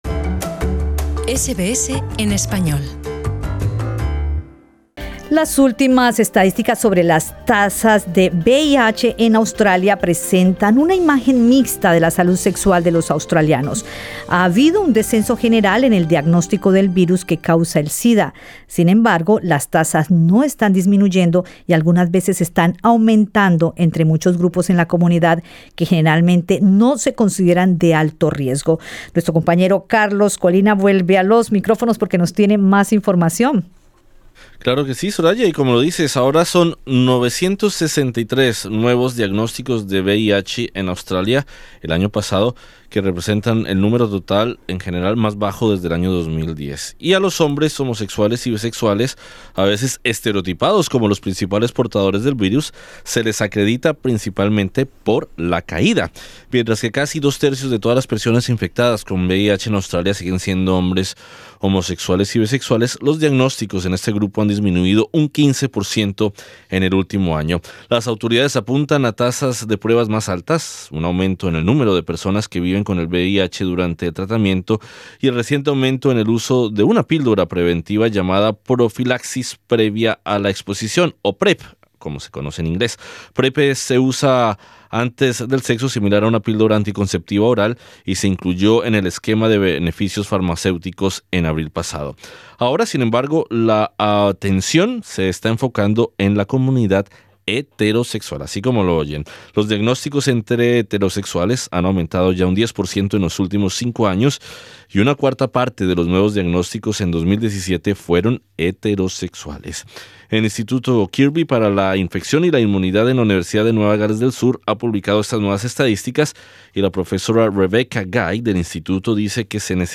Expertos aseguran que se necesita más trabajo para reducir la tasa de diagnóstico en ciertas comunidades, incluidas las de inmigrantes. Entrevista